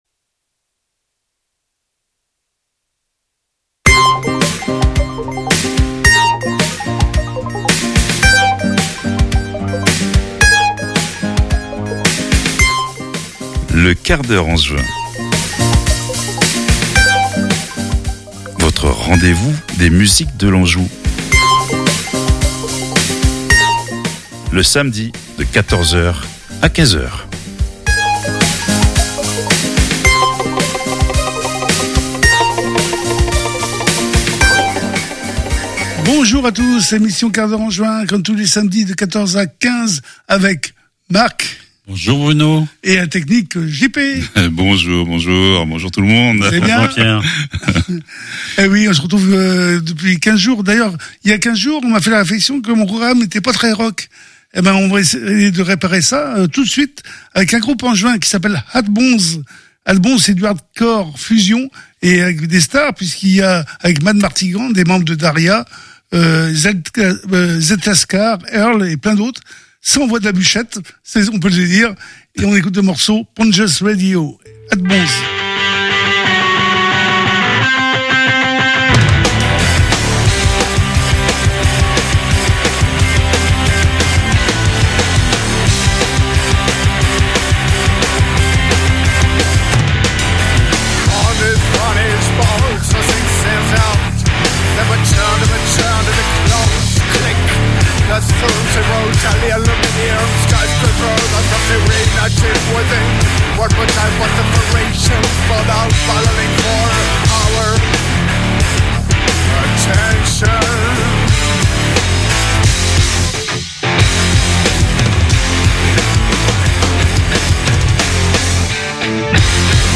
Le QUART D'HEURE ANGEVIN, c'est une heure consacrée à toutes les musiques de l'Anjou. Et elles sont variées.